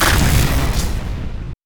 SUTeslaStorm_expb.wav